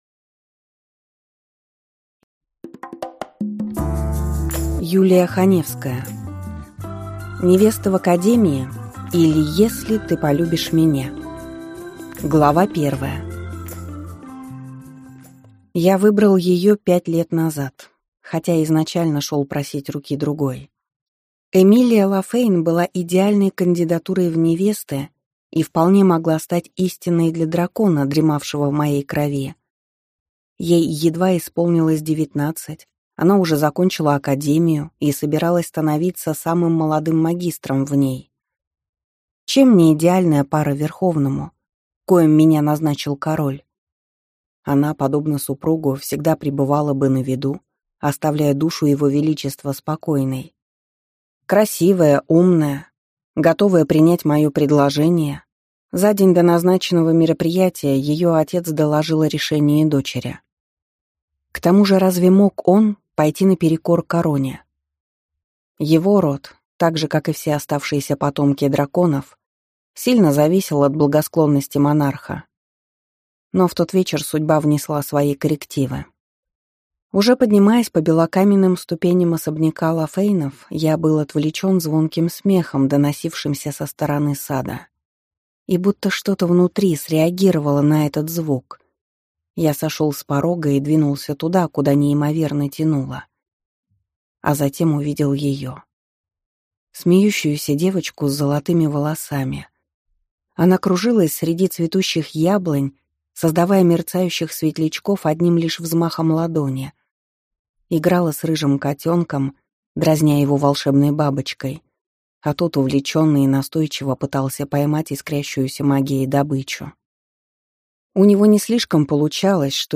Аудиокнига Невеста в академии, или Если ты полюбишь меня | Библиотека аудиокниг